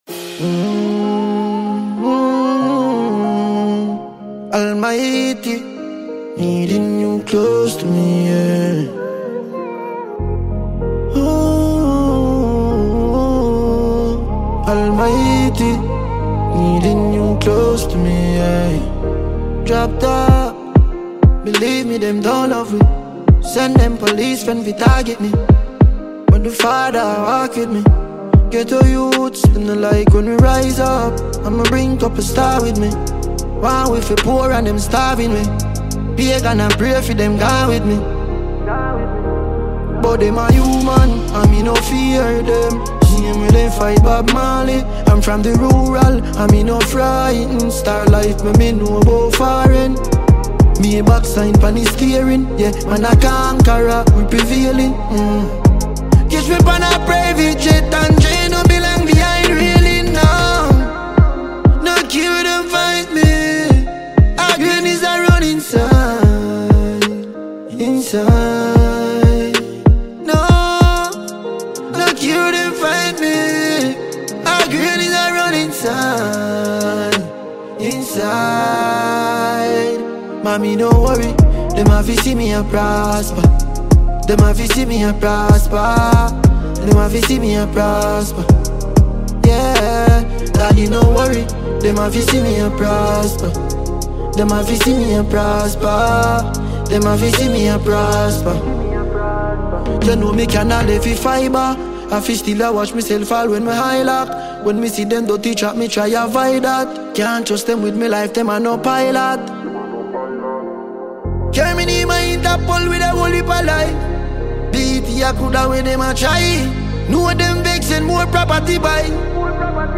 reggae-dancehall